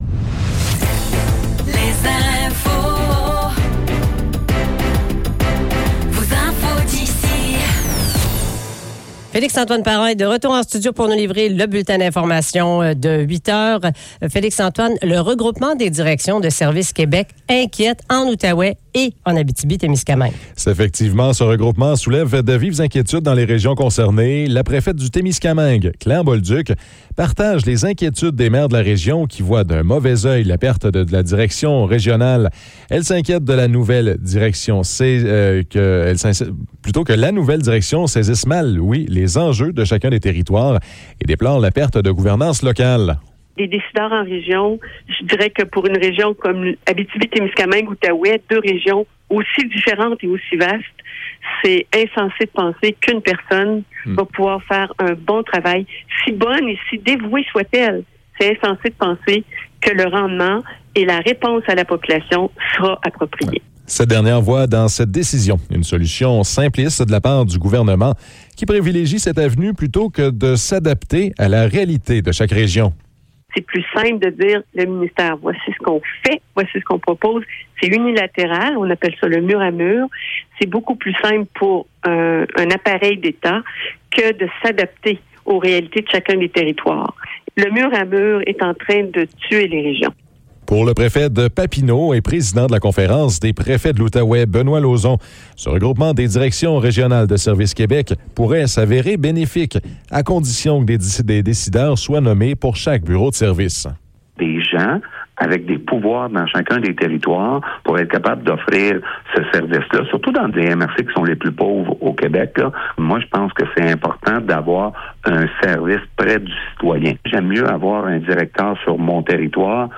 Nouvelles locales - 17 décembre 2024 - 8 h